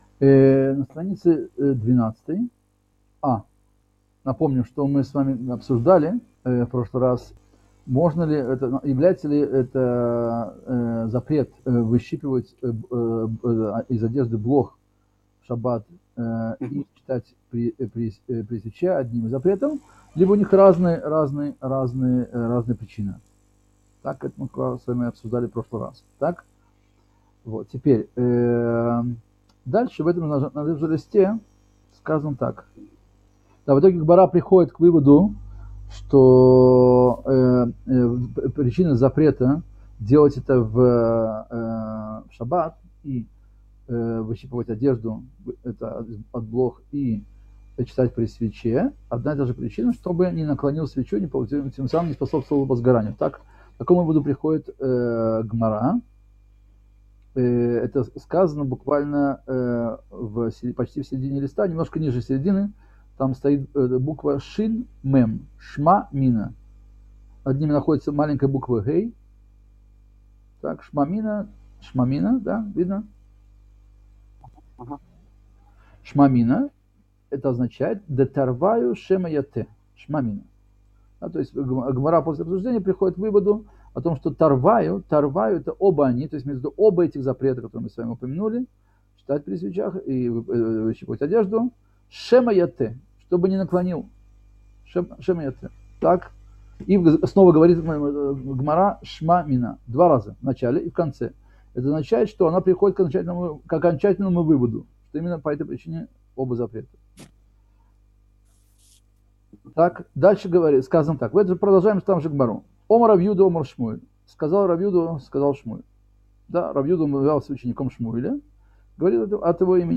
Урок 19.